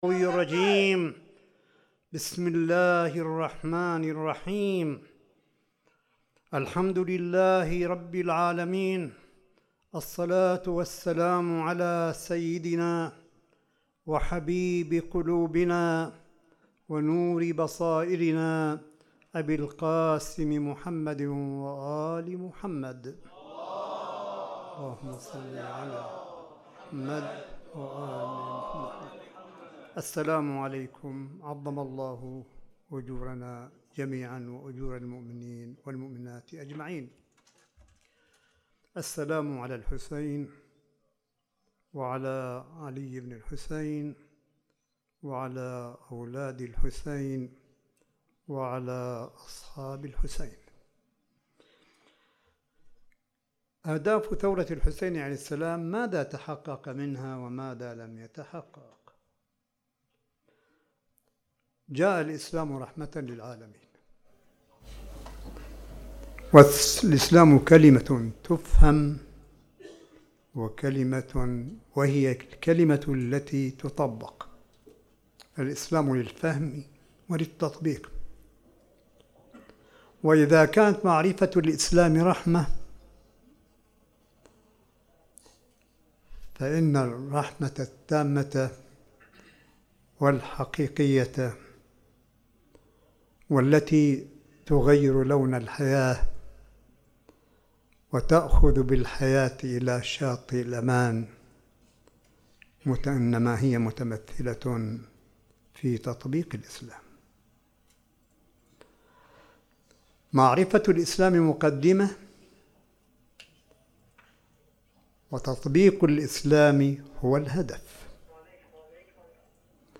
ملف صوتي لكلمة سماحة آية الله الشيخ عيسى أحمد قاسم في موسم عاشوراء ١٤٤١هـ الجزء الثالث مِن (الثورة التي أبقت الإسلام حيًّا) في الحسينية البحرانيّة بـ قم المقدسة – ليلة السادس من محرم – ٥ سبتمبر ٢٠١٩م